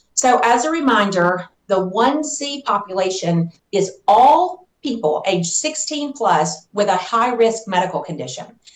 Starting Monday Phase 1c Will Start In 89 Of The States 95 Counties. Tennessee Department Of Health Commissioner Dr Lisa Piercey Explains This Phase…